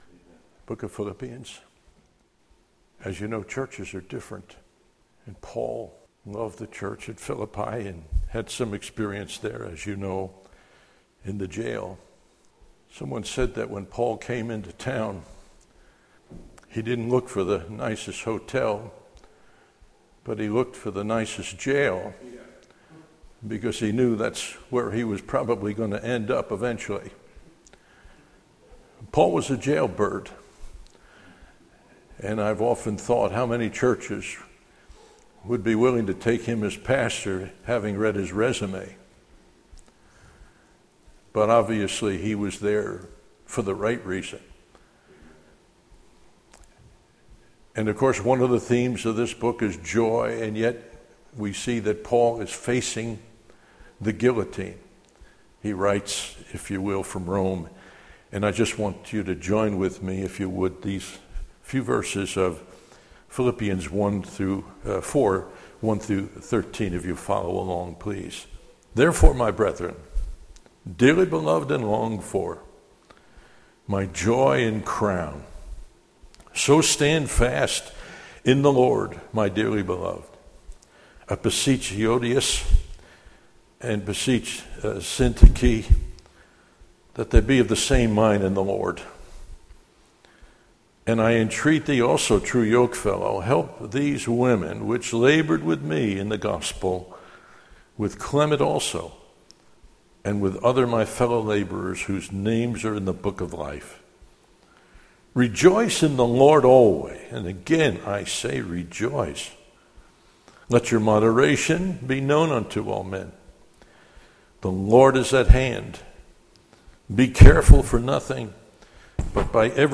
Series: 2015 July Conference Session: Morning Devotion